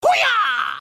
Download Free Kung Fu Sound Effects